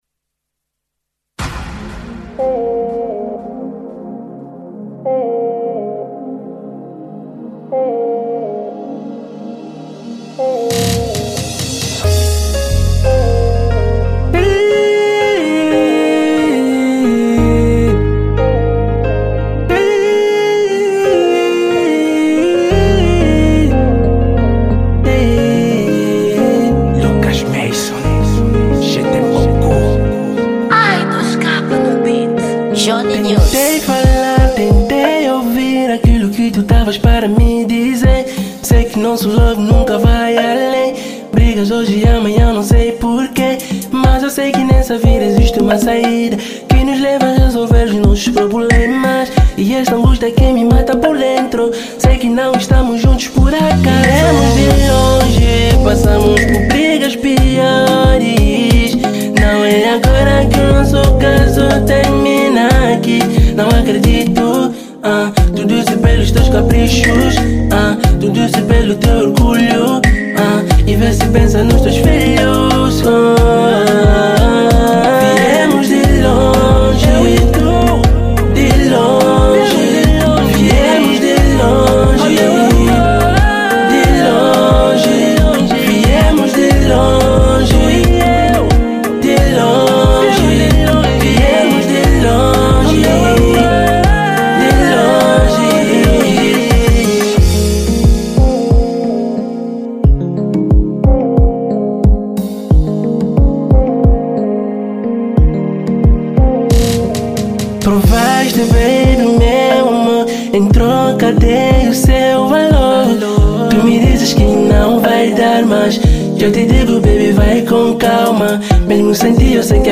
Género musical: Zouk